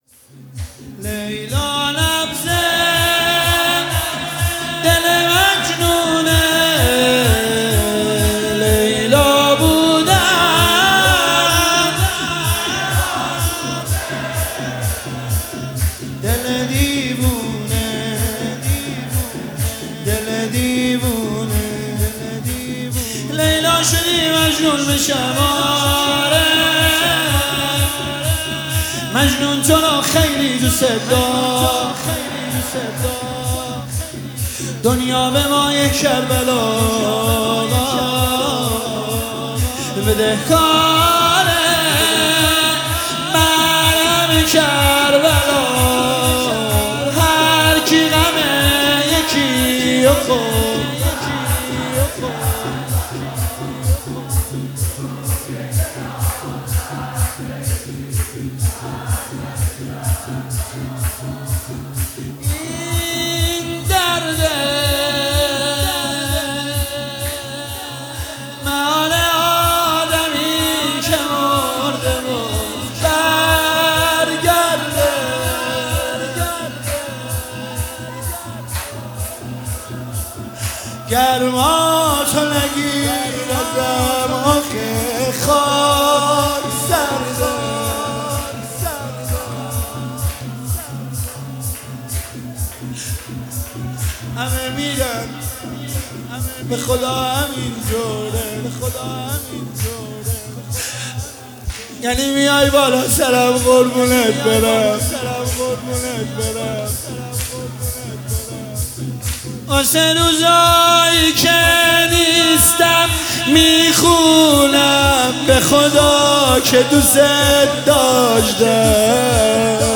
مداحی شور
حرم شهدای گمنام شهرستان ملارد
فاطمیه دوم 1403